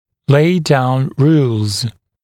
[leɪ daun ruːlz][лэй даун ру:лз]излагать правила